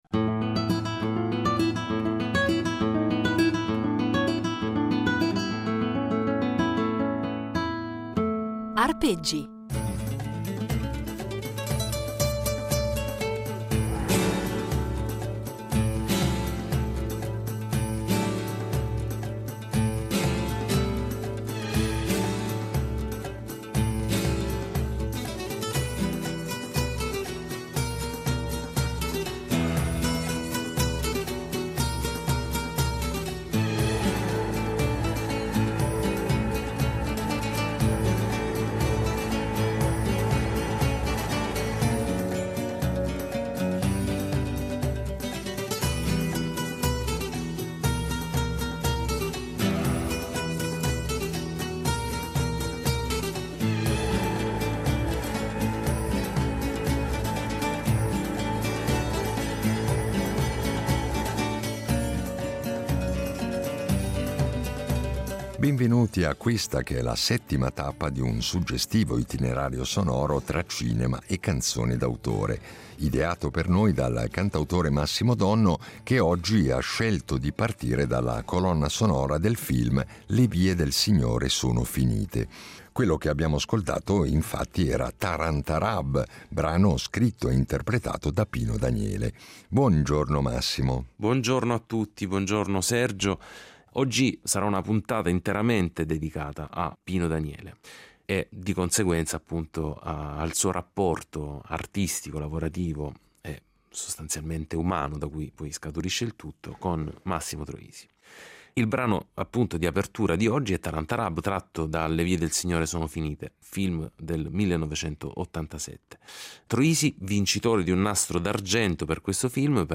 Insieme, andranno quindi ad impreziosire i nostri itinerari sonori con esecuzioni inedite di canzoni che hanno contribuito a rendere indimenticabili alcune pellicole cinematografiche.